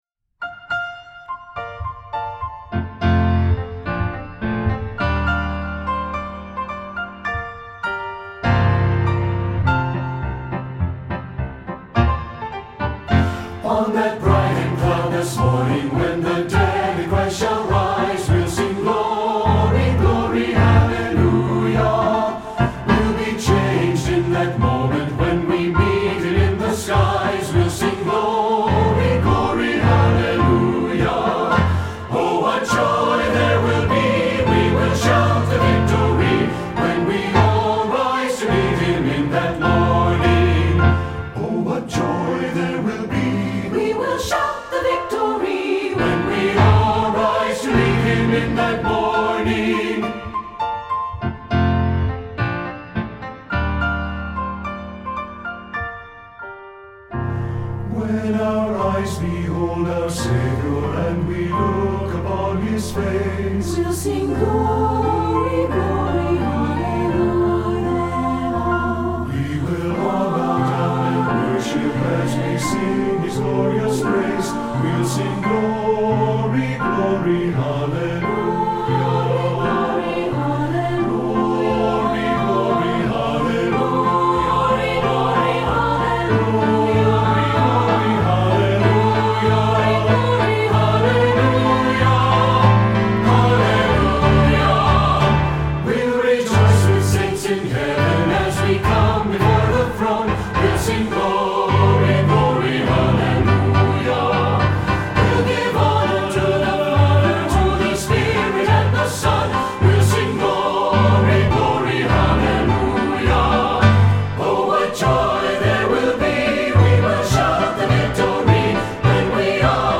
Voicing: SATB and 4 Hand Piano